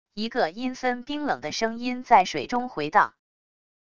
一个阴森冰冷的声音在水中回荡wav音频